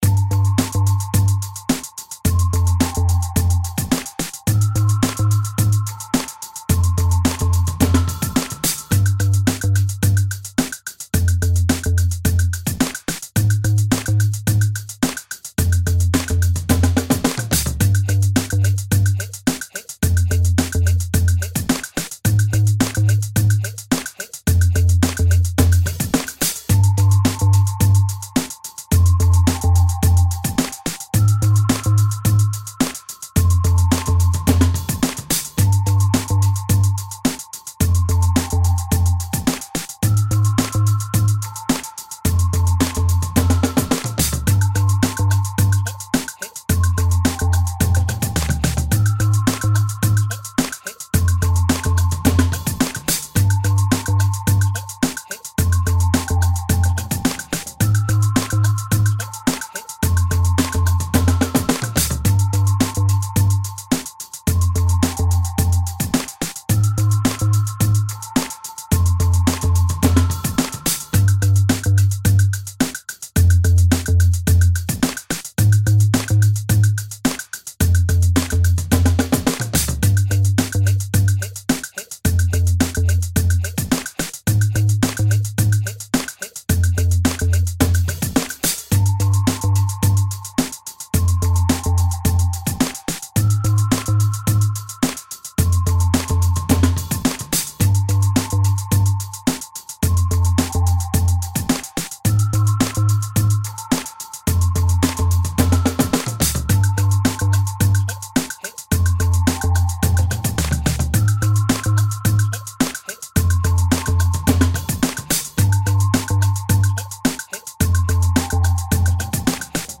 描述：舞蹈和电子音乐|放克
Tag: 合成器